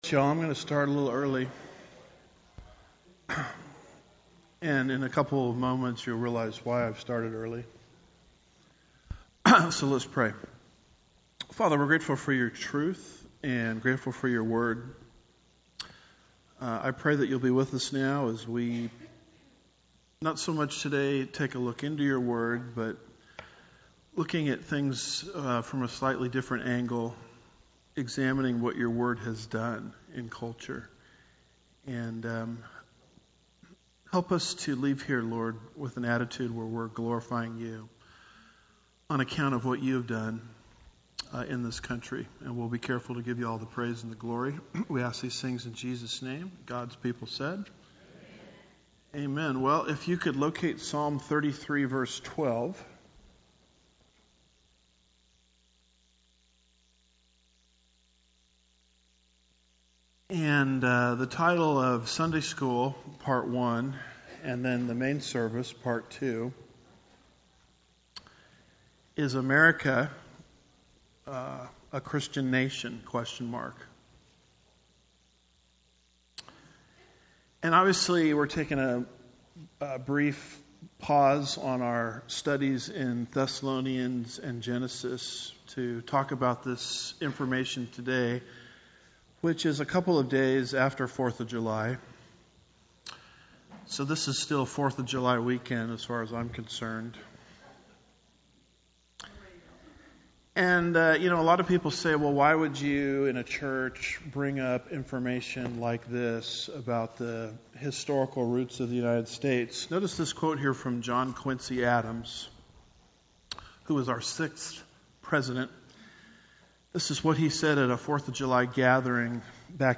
– Part 1 Home / Sermons / Is America a Christian Nation?